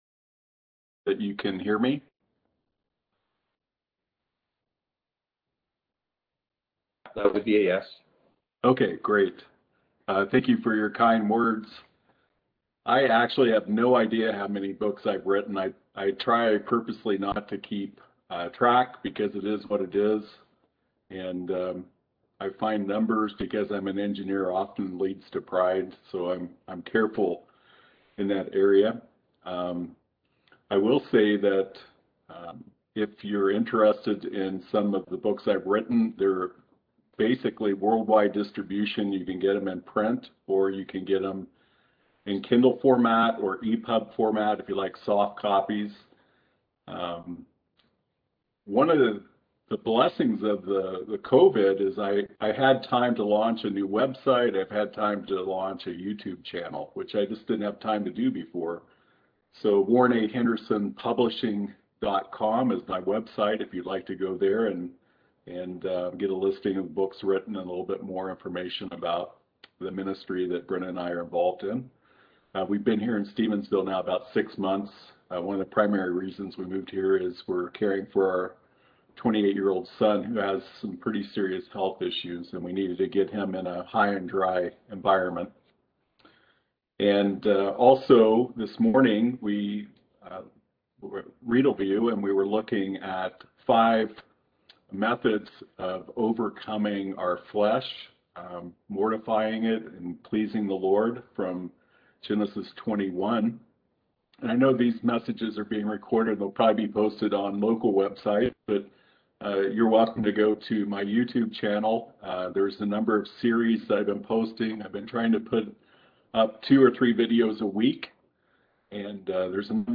Series: Easter Conference Passage: Genesis 22-25 Service Type: Seminar